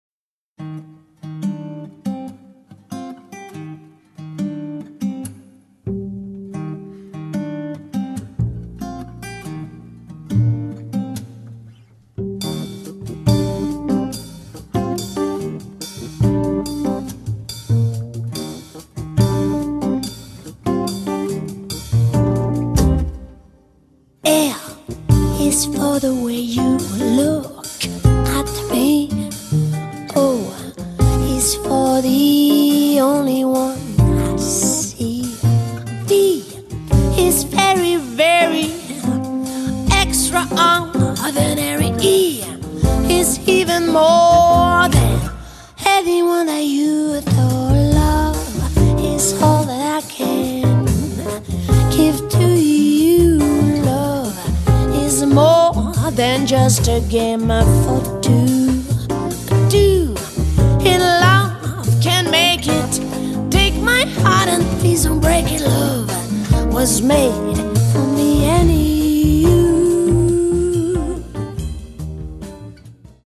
chitarre, voce
contrabbasso
batteria
piano
tromba
qui con delle coloriture tra il country ed il blues.